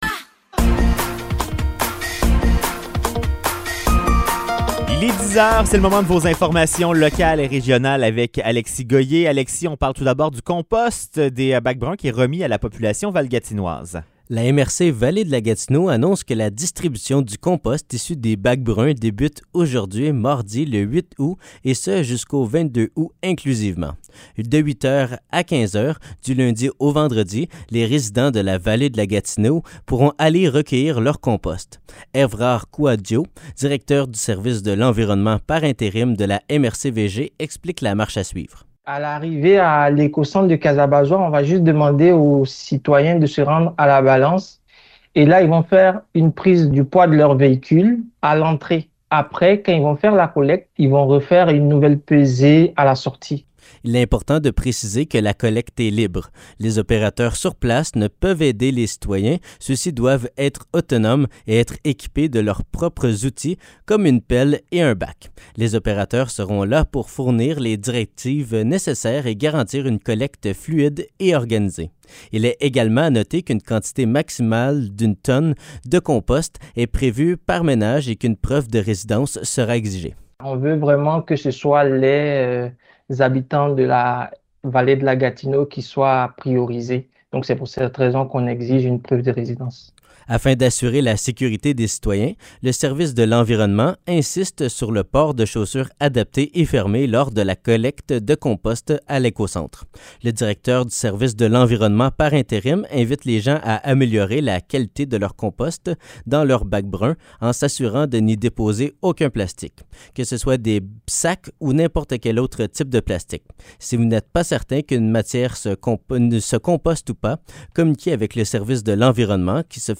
Nouvelles locales - 8 août 2023 - 10 h